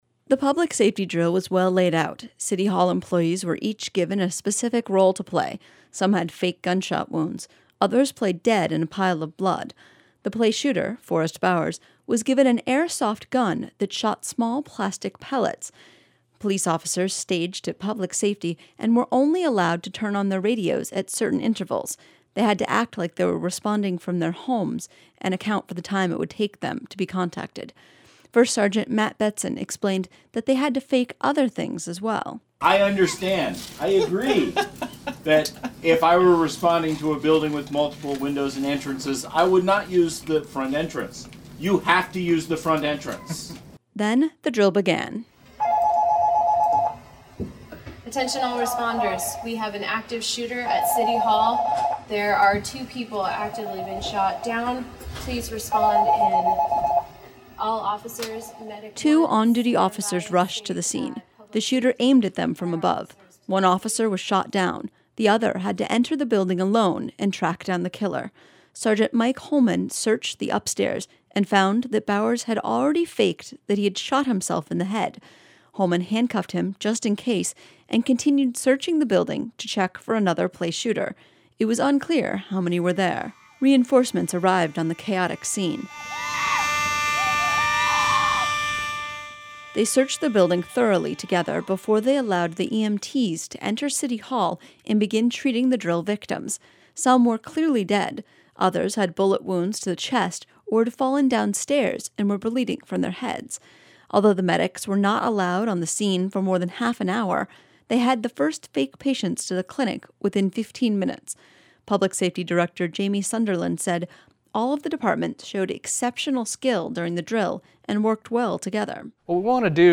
Unalaska, AK – The Department of Public Safety staged an active shooter drill at city hall on Friday to train police officers and emergency responders. KUCB was at the scene.